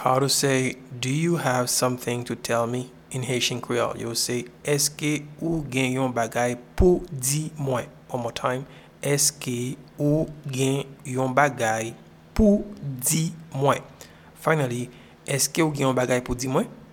Pronunciation and Transcript:
Do-you-have-something-to-tell-me-Eske-ou-gen-yon-bagay-pou-di-mwen.mp3